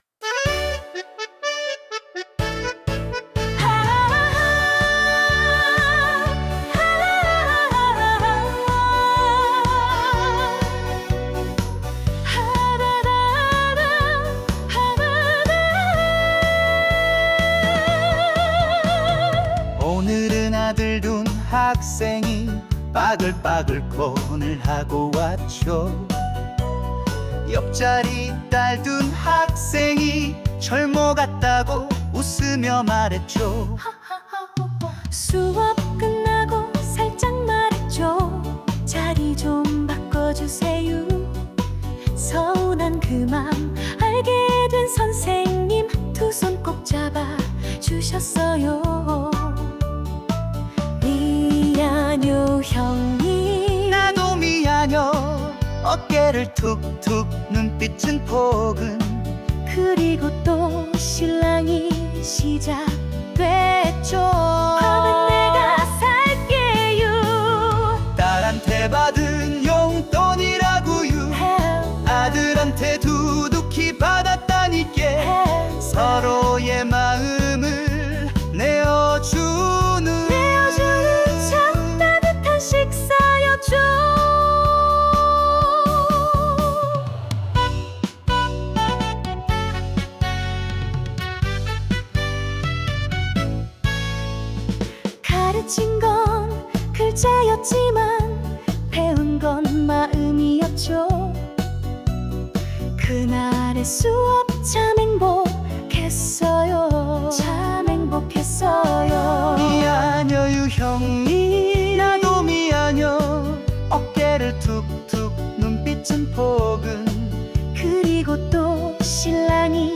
글 속의 장면을 따라가듯 노랫말은 유머와 따스함으로 흘러갑니다.
음악 스타일은 어르신들을 위한 트로트로 만들어 봤습니다.